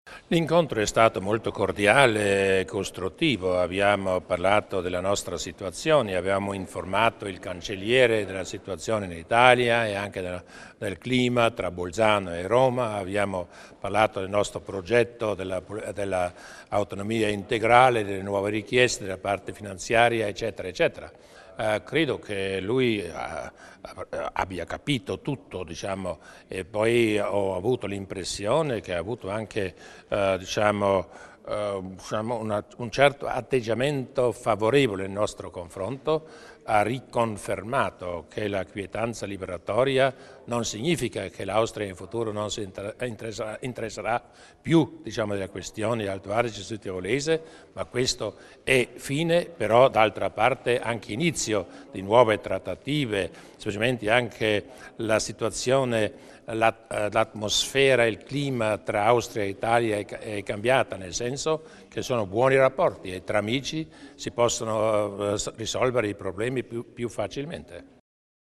Il PresidenteDurnwalder illustra i punti salienti dell'incontro con il cancelliere Faymann